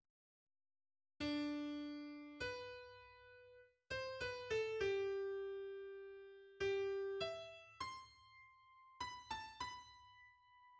klinkt een lyrische sopraan begeleid door een koor.